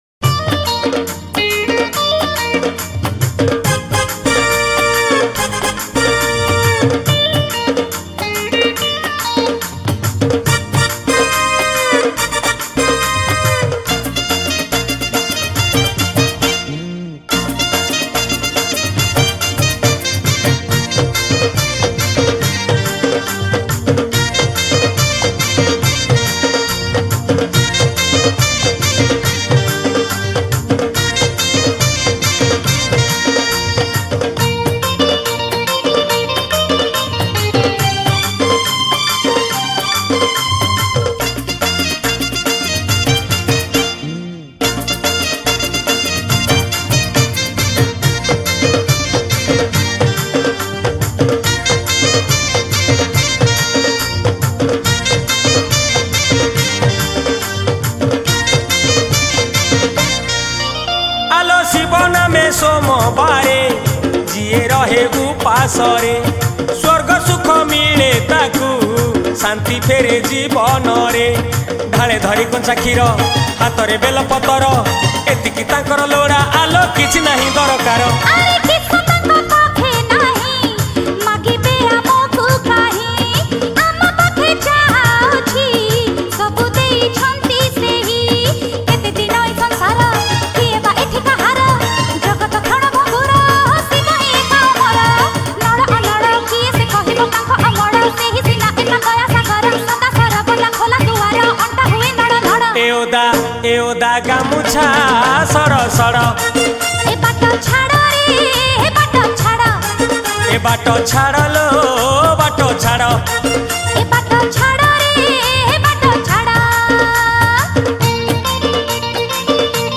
Category : Bolbum Special Song